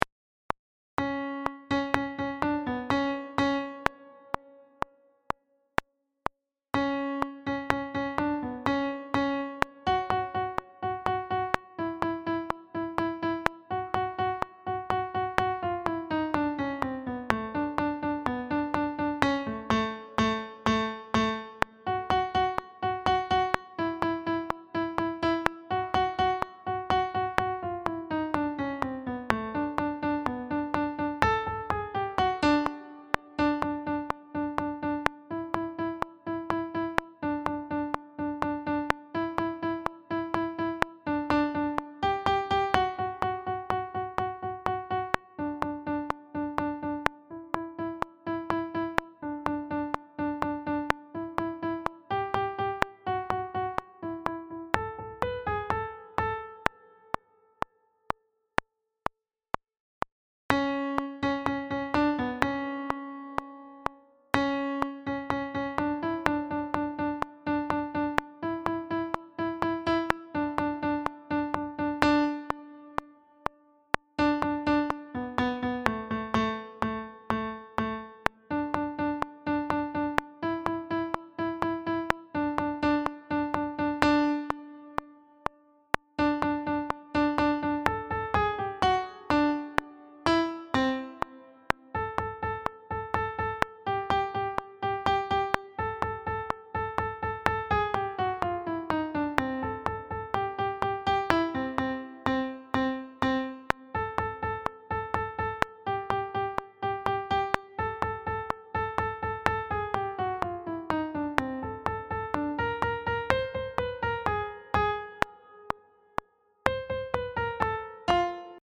Tikanpolkka harjoitustempo
Tikanpolkka Altto  bpm125.mp3